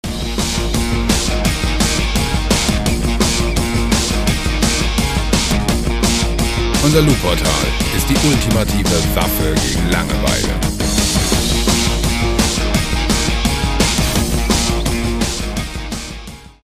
Heavy Metal Loops
Musikstil: Hard Rock
Tempo: 170 bpm